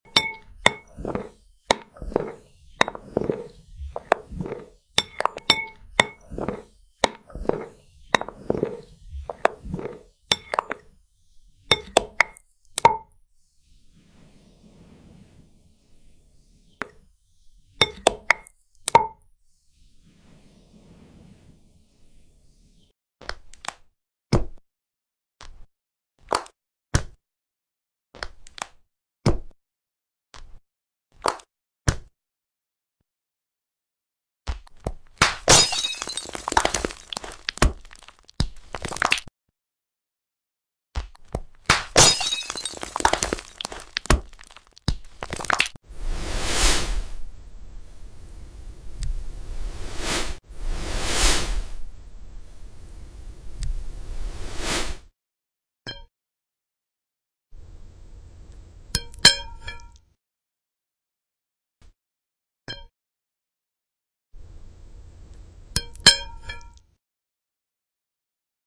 🎧✨ From the soothing drizzle of molten chocolate to the satisfying crackle of glassy caramel and the hypnotic jiggle of clear gelatin, this is pure ASMR bliss.